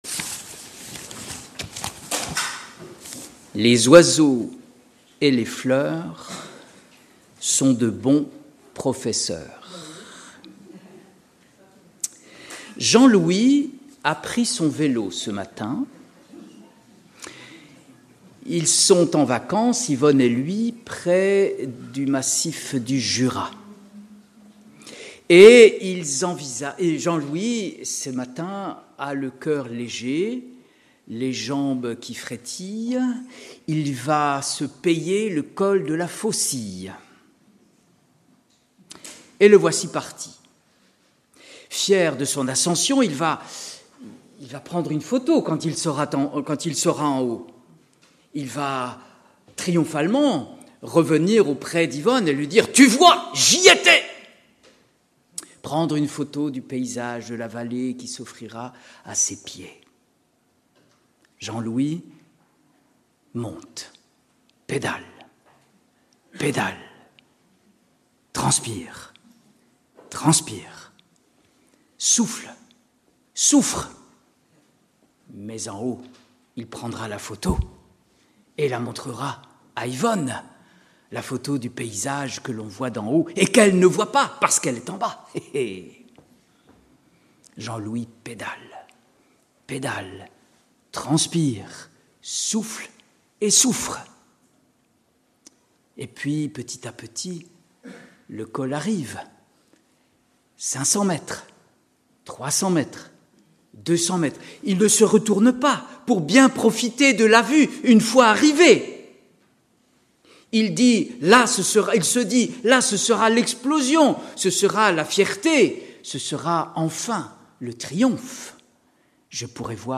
Culte du dimanche 3 août 2025 – Église de La Bonne Nouvelle
Prédication